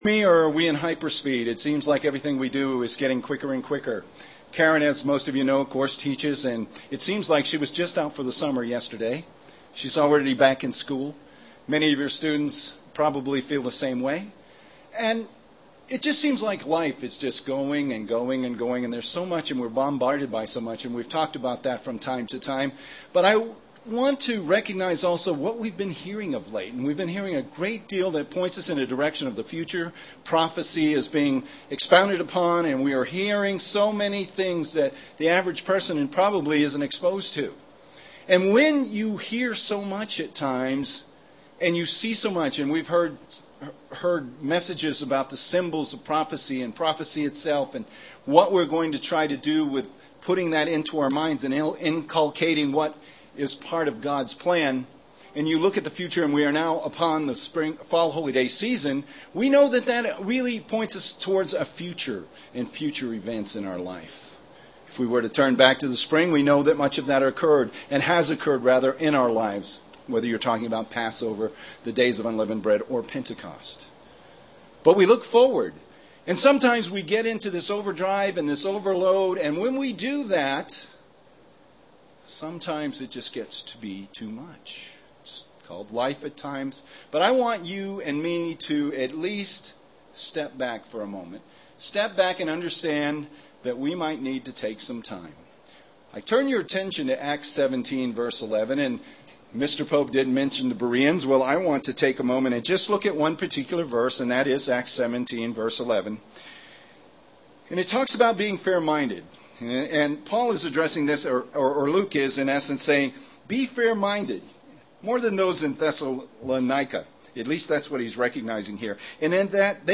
Given in Albuquerque, NM
UCG Sermon Studying the bible?